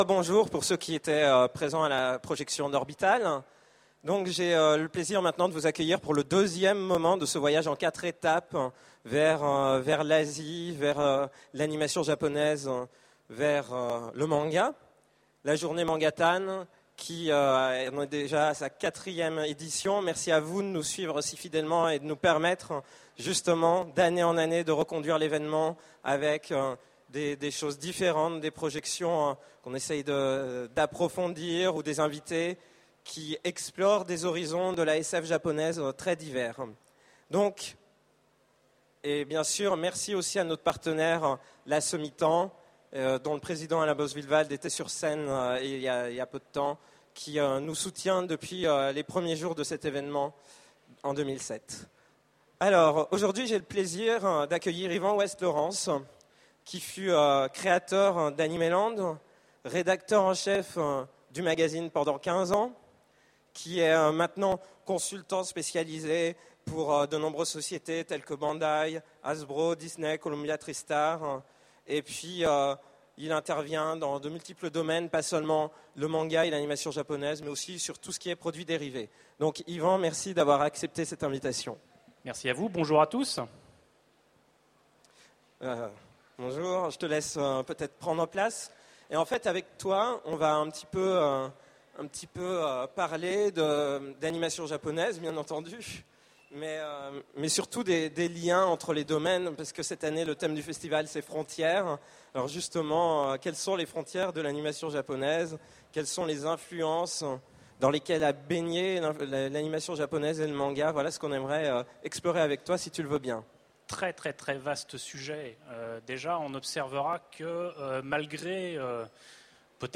Utopiales 2010 : Conférence Manga et Asie, aux frontières du genre
Conférence Manga et Asie